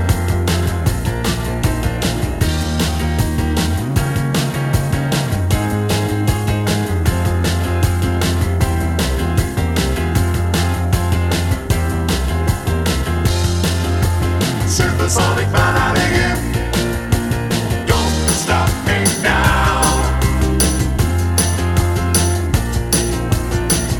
One Semitone Down Rock 3:40 Buy £1.50